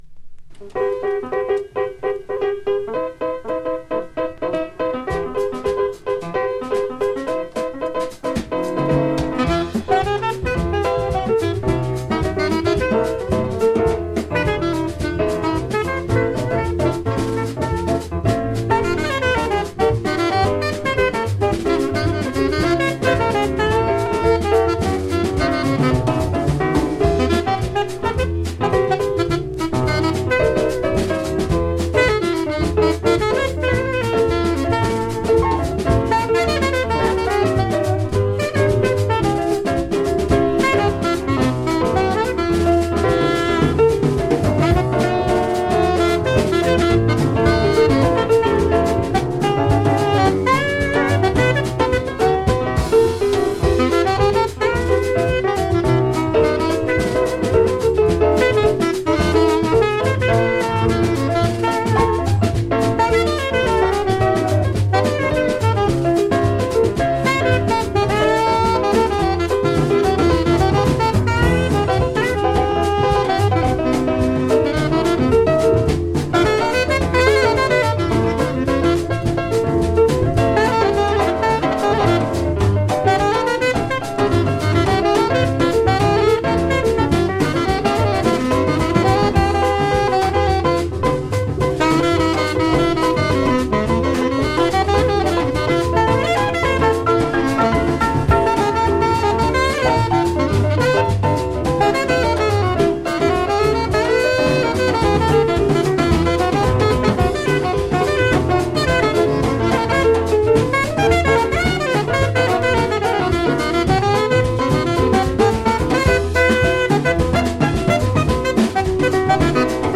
富山県高岡市におけるライヴ録音です。
ラテンテイストの「
ピアノトリオ、またはサックスが入ってのカルテットでの演奏です。